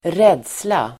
Uttal: [²r'ed:sla]